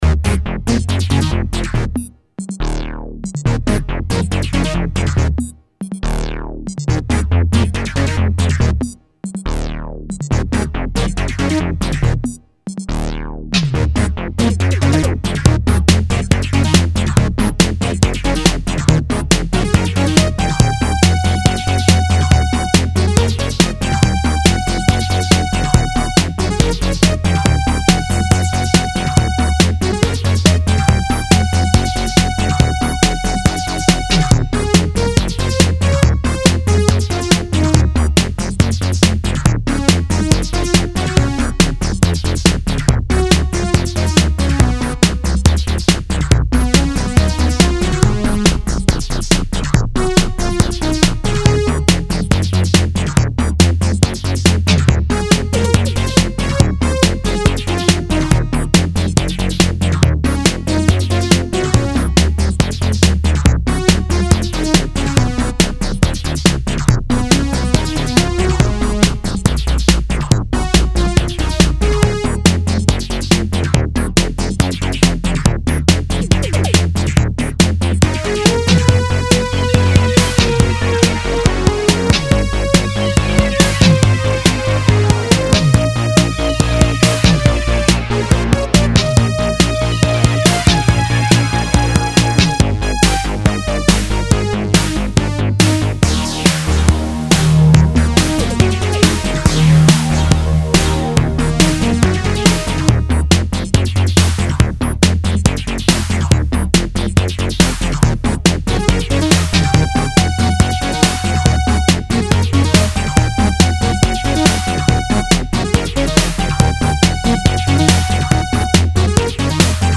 New Track – Some Nostalgic synthpop!
instrumental track